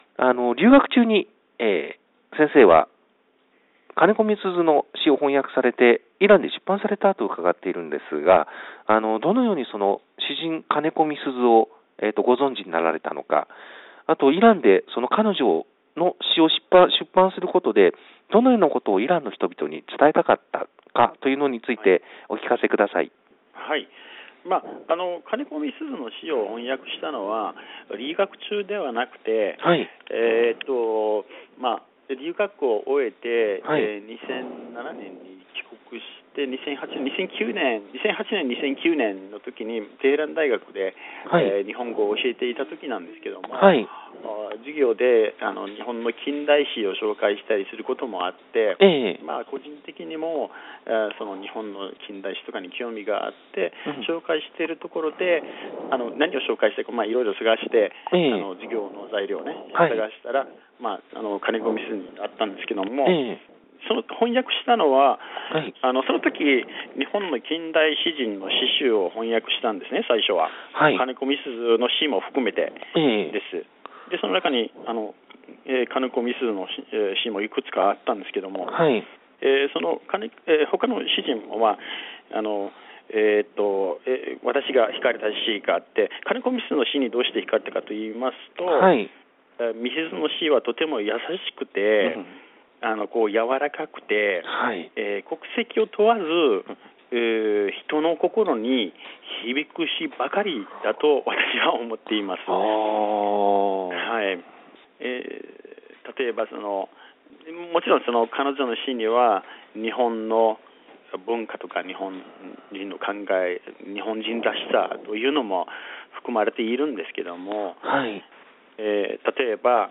大阪大学外国語学部専任講師へのインタビュー（３）